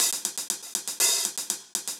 Index of /musicradar/ultimate-hihat-samples/120bpm
UHH_AcoustiHatA_120-01.wav